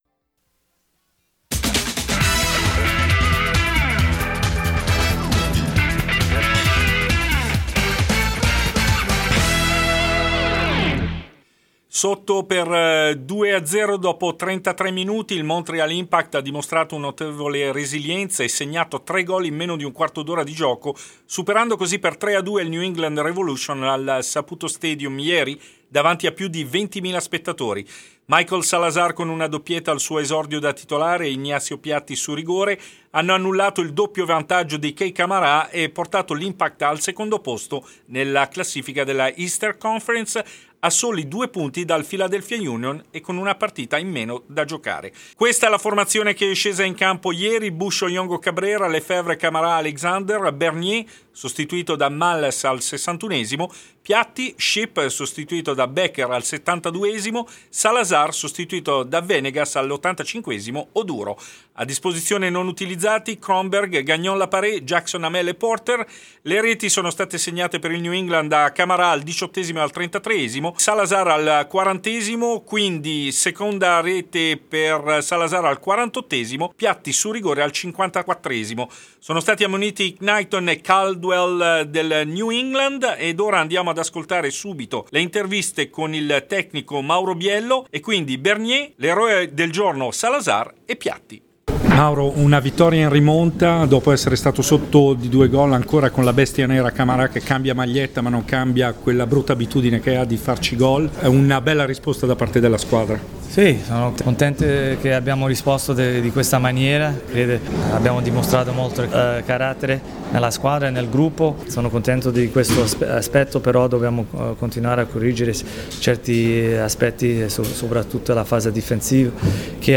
Servizio completo: interviste postpartita